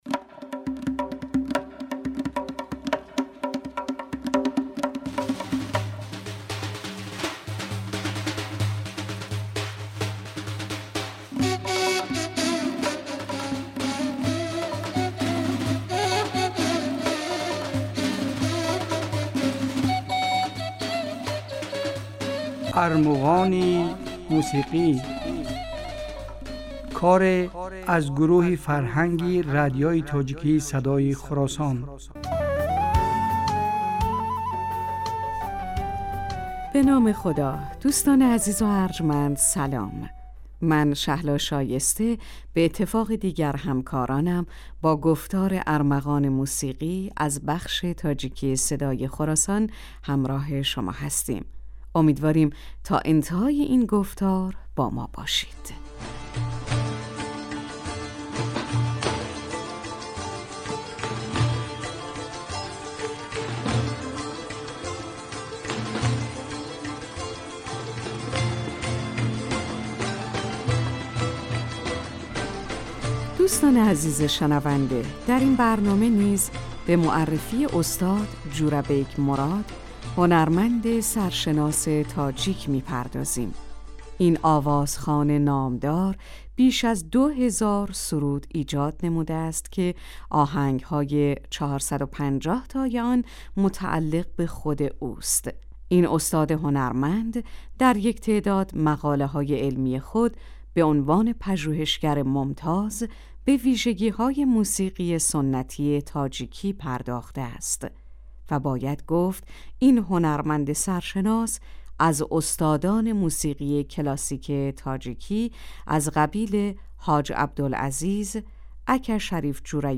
Дар ин барномаҳо кӯшиш мекунем, ки беҳтарин ва зеботарин мусиқии тоҷикӣ ва эрониро ба шумо пешкаш кунем.